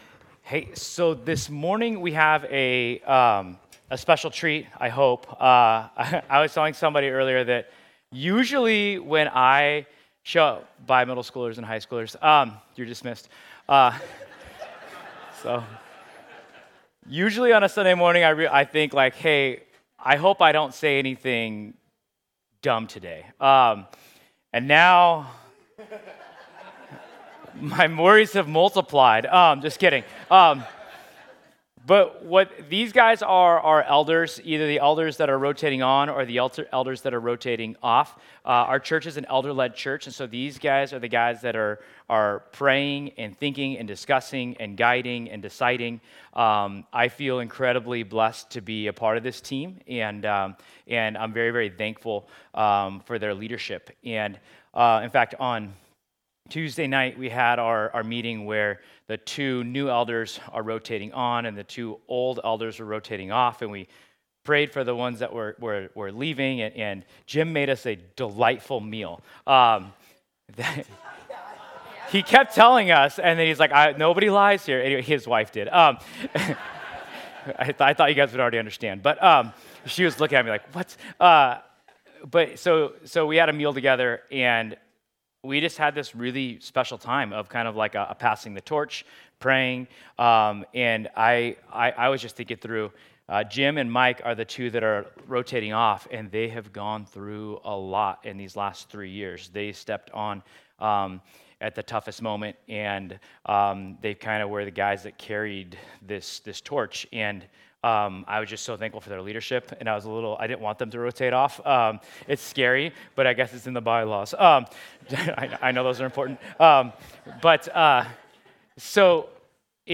Service Type: Sunday
You’ll hear directly from our Elder team—not just about plans or programs, but about how God is shaping their hearts, stirring their faith, and leading them as they shepherd our church. We’ll talk about what excites them most, what they’re praying for, and where they believe God is calling CBC in this next season.